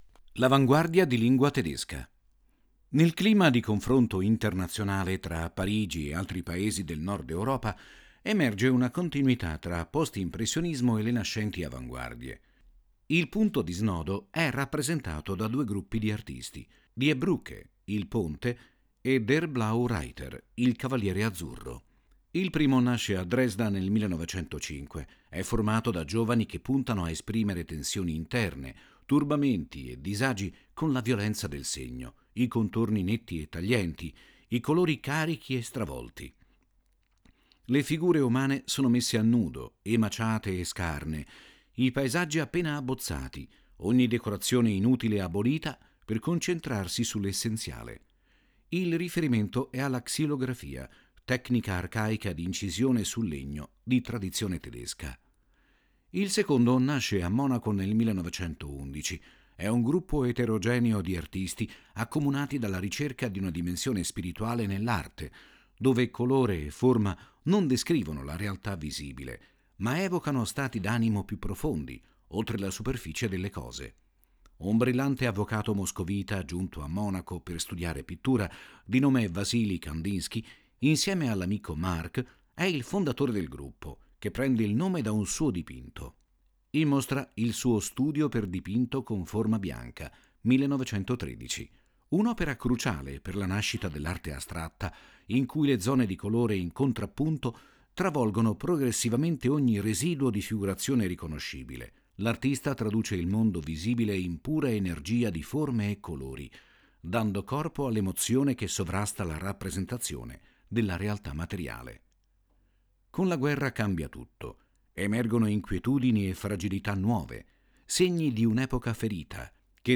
• 5 AUDIODESCRIZIONI DI SEZIONE che accompagnano il visitatore nel percorso espositivo, fruibili tramite QR code
Audiodescrizioni